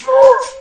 Vox [ Kilt Me ].wav